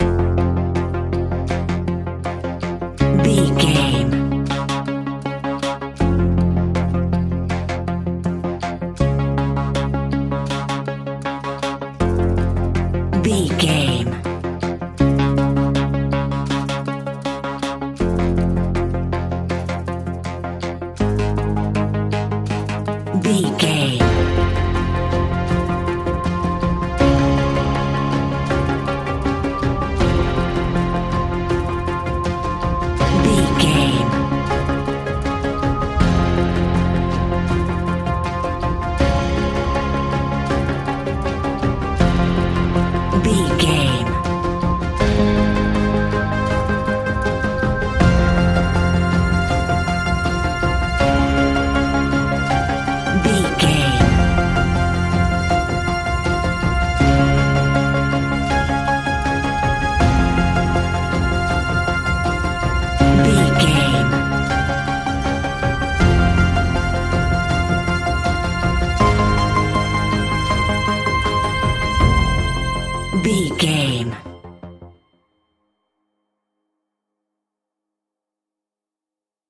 Aeolian/Minor
ominous
dark
haunting
eerie
futuristic
industrial
synthesiser
drums
horror music